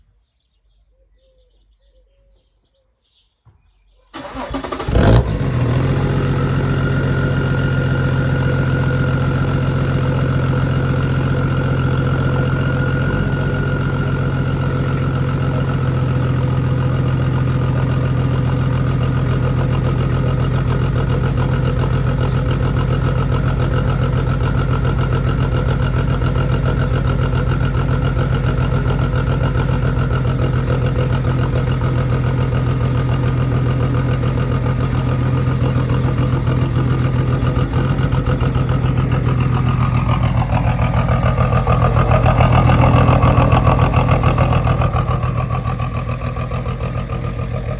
BMW E34 525 TDS straight sound effects free download
BMW E34 525 TDS straight pipe cold start